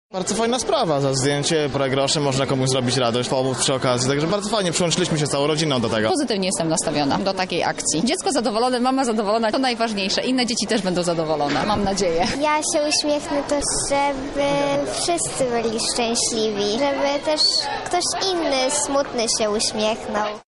Osoby, które chciały się sfotografować zgodnie twierdziły, że każda akcja na rzecz dzieci z hospicjum jest ważna i potrzebna.